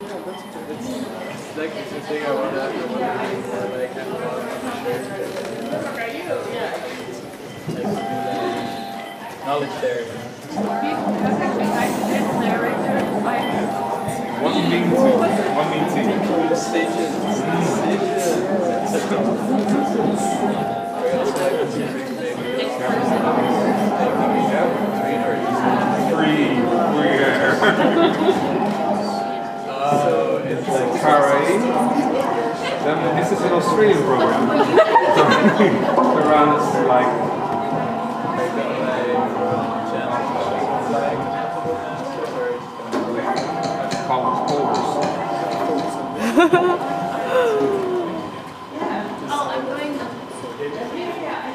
Room noise